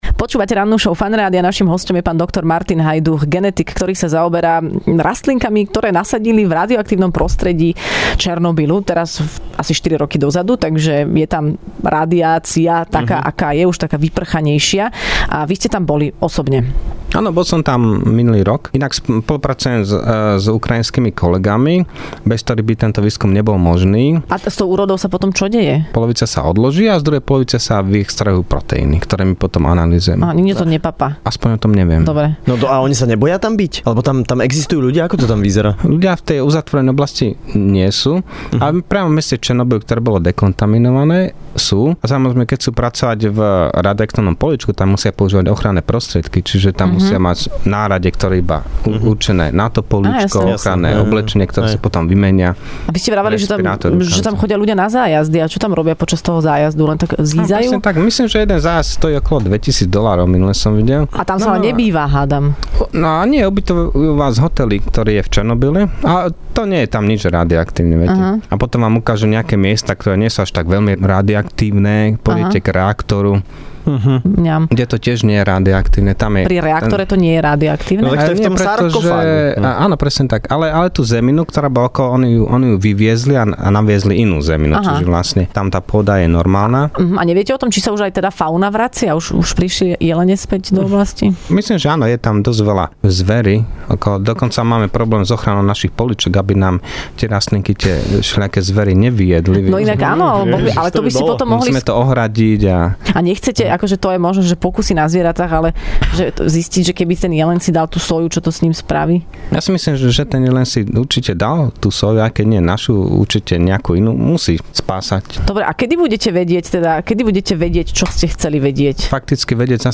Hosťom v Rannej šou bol genetik